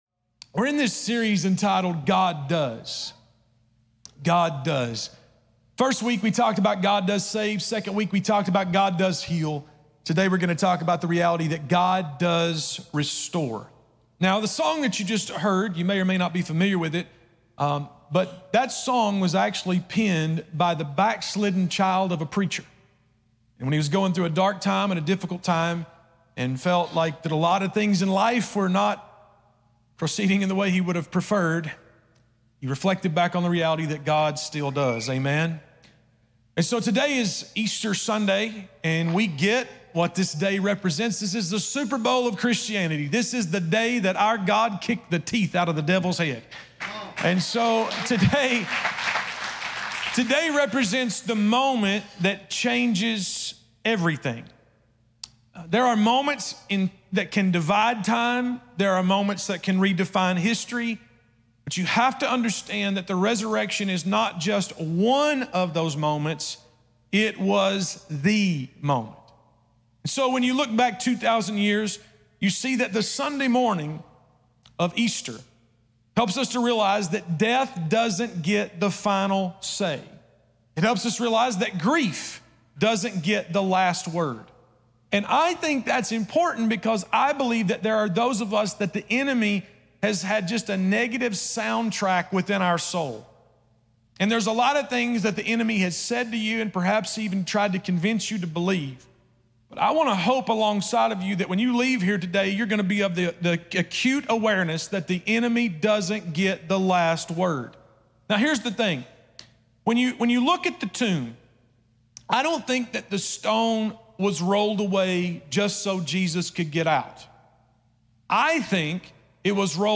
In this powerful Easter message, we dive into the resurrection of Jesus Christ and how it changes everything. Discover how God restores what is broken, revives what is buried, and reclaims what is bound.